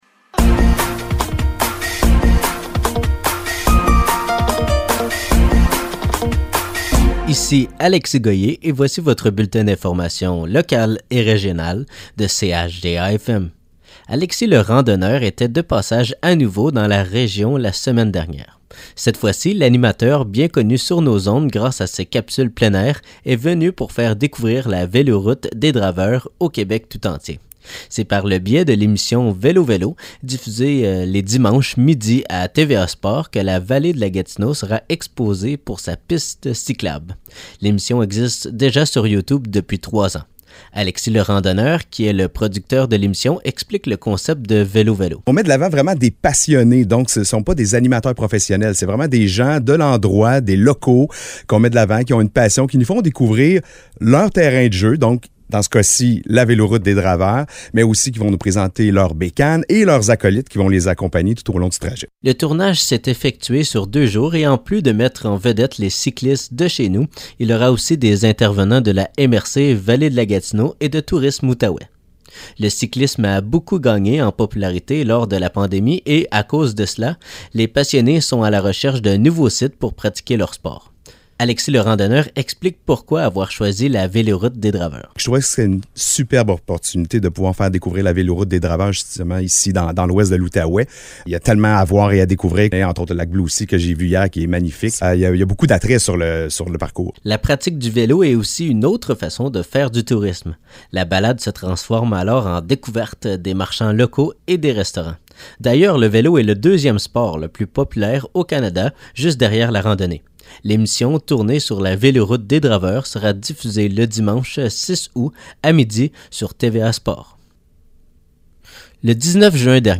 Nouvelles locales - 4 juillet 2023 - 12 h